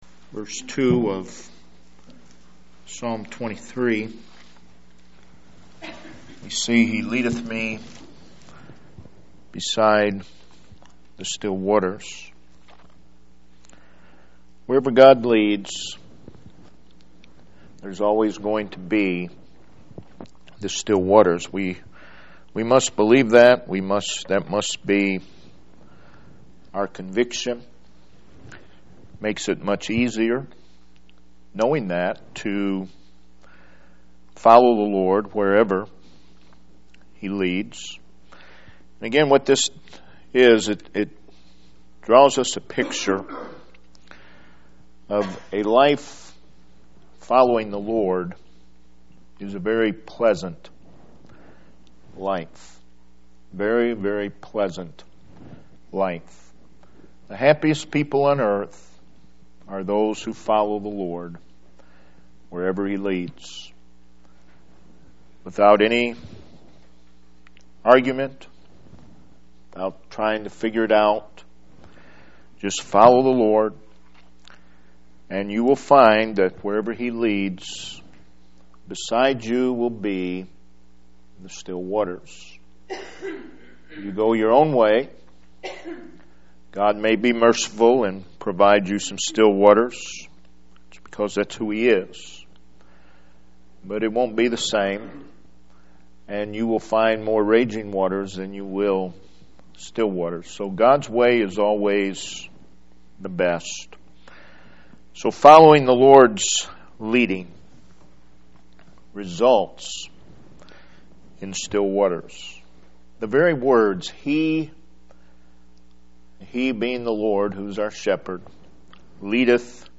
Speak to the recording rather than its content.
February 2017 - Weekly Sunday Services This page presents the Sunday School lessons recorded at Home Missionary Baptist Church during our Sunday Services.